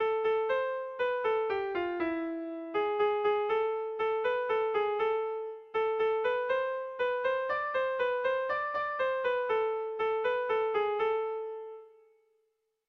Kopla handia
ABD